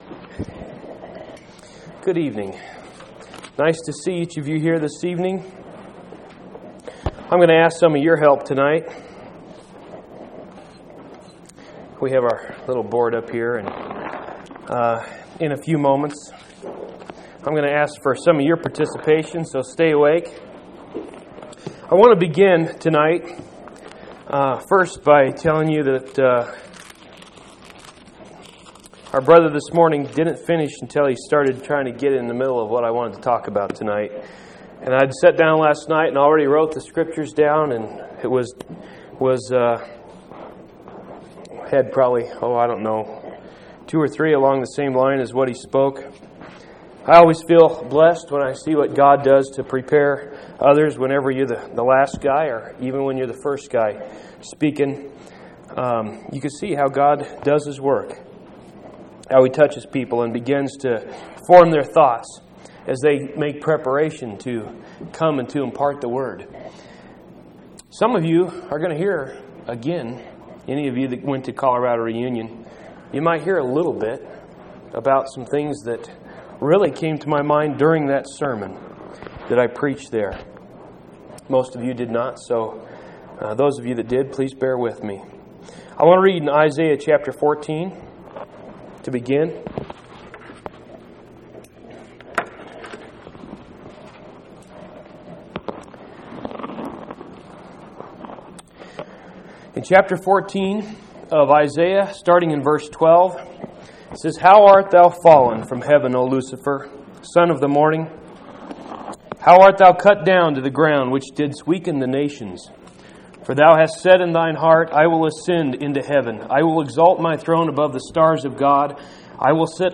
6/28/1998 Location: Phoenix Local Event